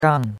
gang4.mp3